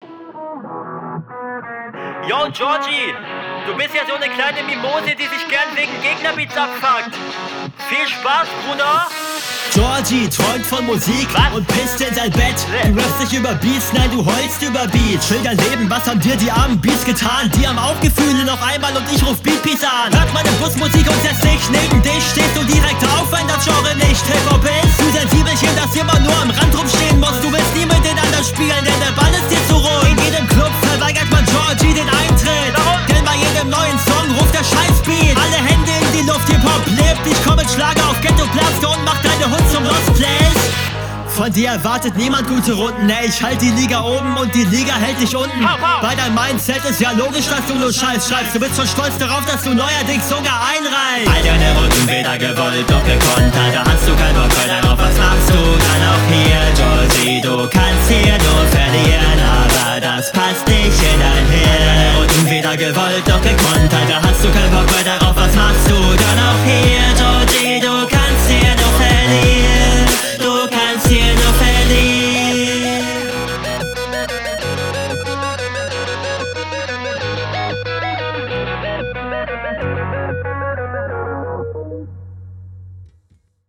Du heulst über beats find ich gut, Beat peace ok, er ruft "Scheiß beat" geil …